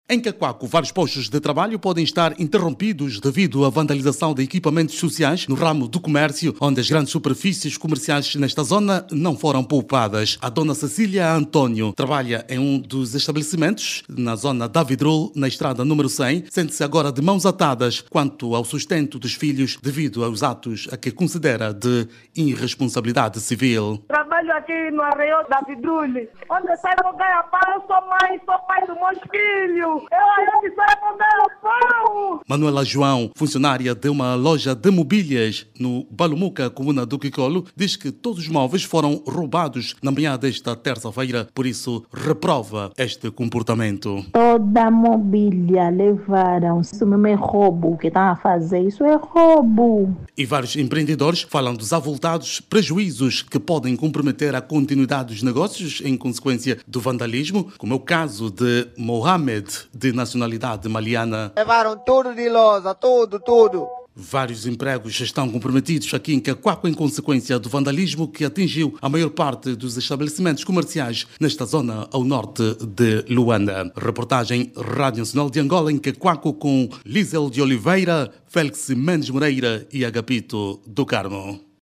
Funcionários de empreendimentos saqueados durante os actos de vandalismo temem ficar desempregado .  Nesta altura vários estabelecimentos comerciais foram encerrados e os proprietários de lojas vandalizadas  falam em perdas avultadas.  Jornalista